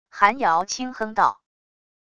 韩瑶轻哼道wav音频生成系统WAV Audio Player